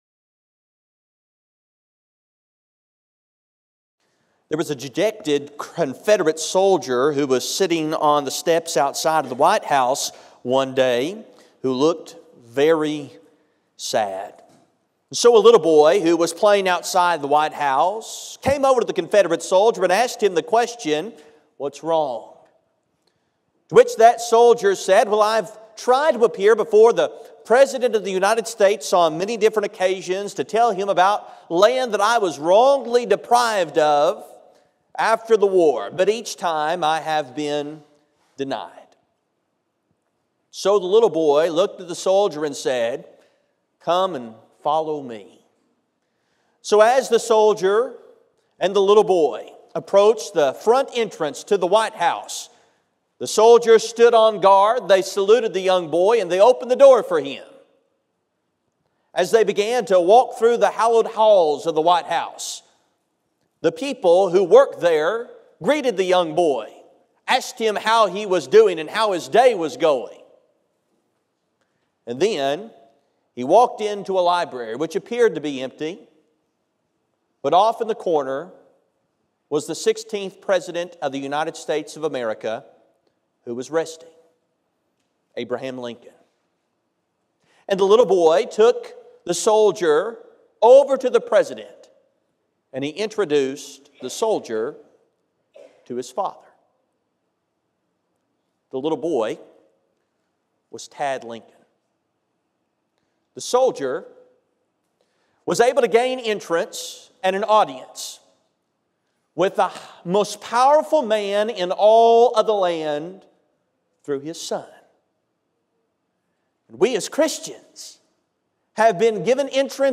The sermon is from our live stream on 11/3/2024